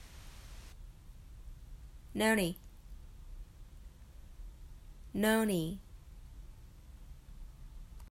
発音音声